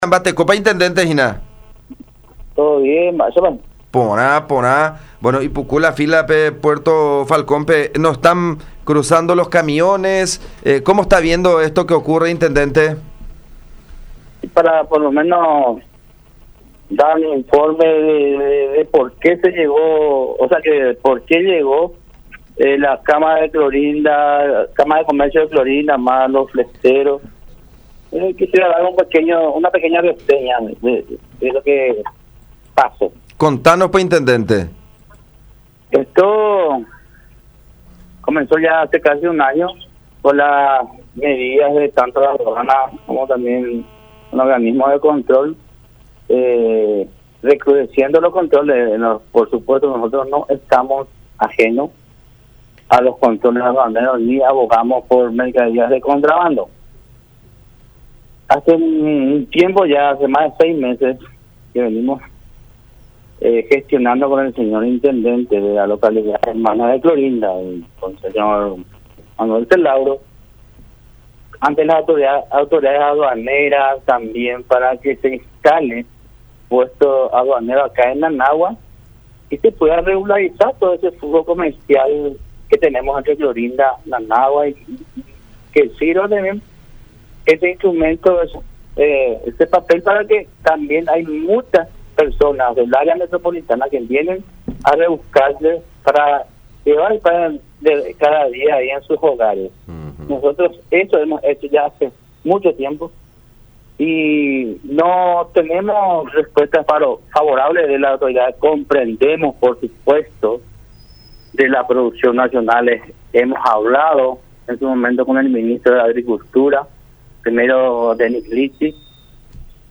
El intendente de Nanawa, Javier Núñez, explicó que las diferencias con los argentinos comenzaron hace ya un año con las restricciones del lado paraguayo.